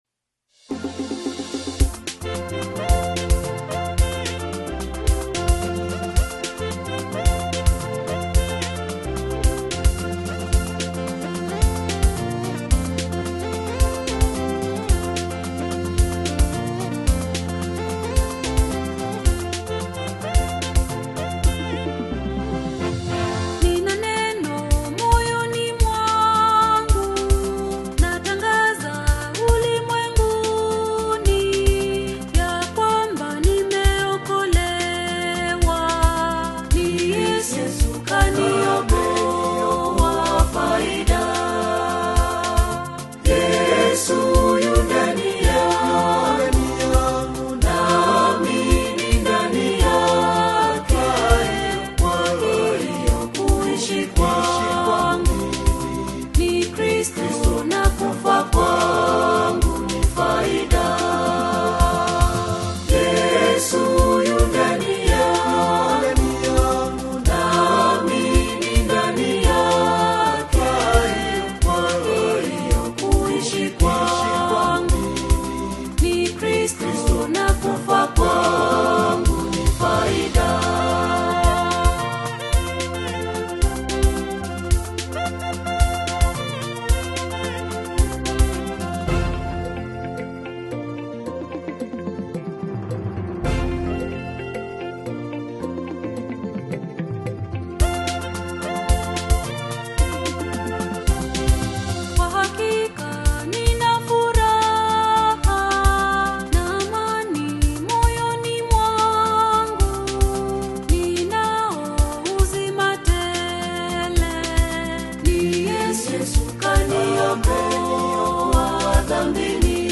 Gospel music track
Kenyan gospel artist